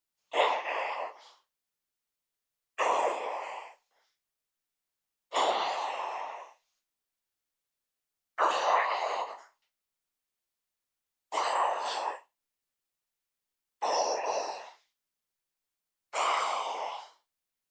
El resultado le da un tono áspero con aire muy interesante. Seguidamente le aplico un Phaser para que le de profundidad y ayude a empastar mejor con la pista principal.
Así suena la pista sola:
Orco-whisper.mp3